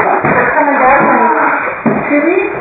Some E.V.P. captured at St. Mary's Hospital:
EVP_Ahh_StM   During the Ghost Party, while walking in the basement, this EVP as captured.